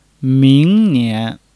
ming2--nian2.mp3